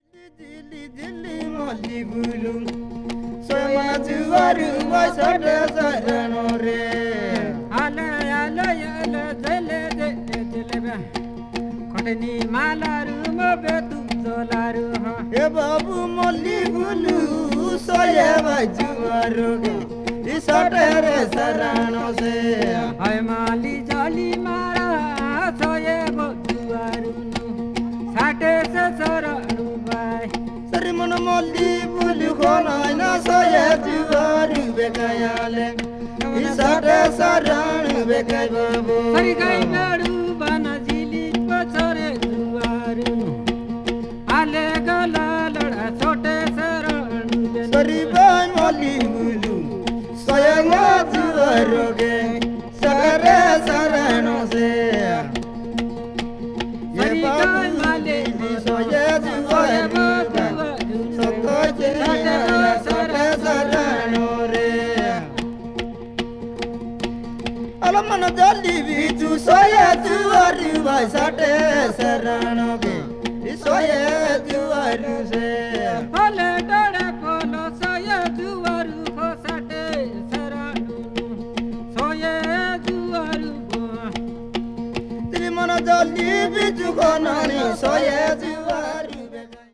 media : EX-/EX-(わずかなチリノイズ/一部軽いチリノイズが入る箇所あり)
ベンガル湾に面するインド東部の州、オリッサの民族音楽の現地録音を収録。
ethnic music   folk   india   oriental   traditional